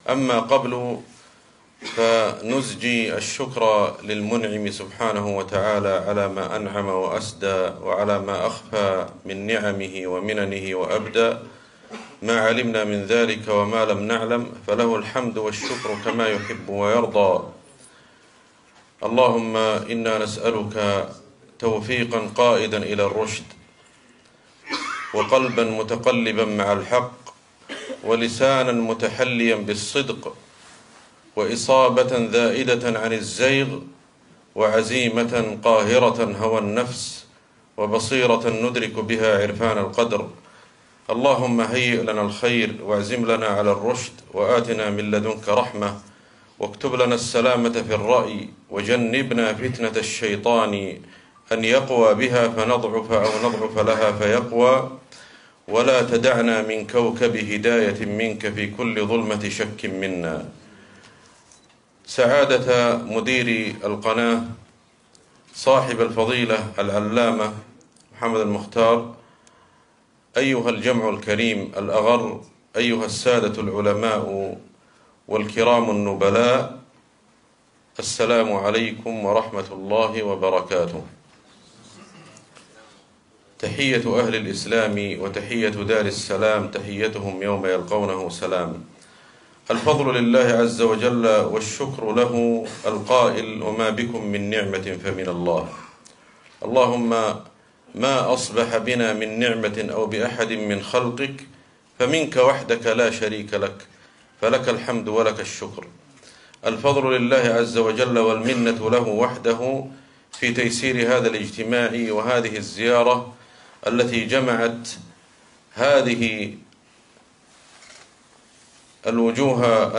كلمة الشيخ أحمد الحذيفي خلال زيارته لمحطة الجهوية لإذاعة موريتانيا بسيليبابي > زيارة الشيخ أحمد الحذيفي لـدولة موريتانيا > تلاوات و جهود الشيخ أحمد الحذيفي > المزيد - تلاوات الحرمين